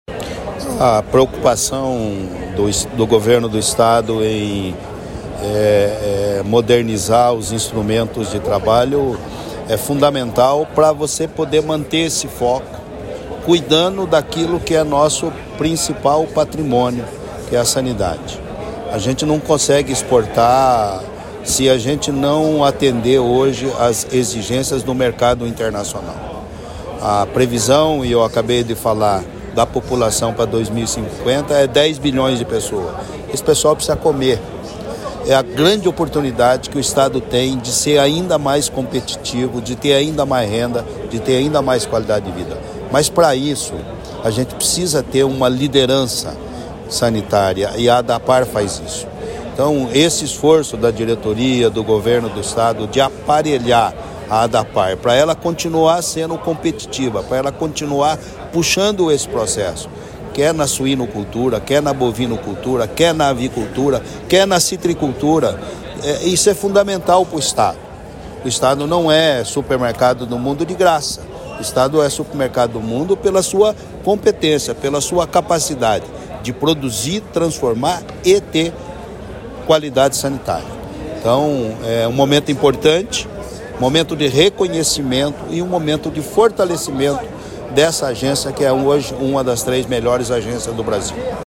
Sonora do secretário da Agricultura e do Abastecimento, Natalino Avance, sobre a renovação da frota de veículos da Adapar